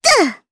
Miruru-Vox_Landing_jp.wav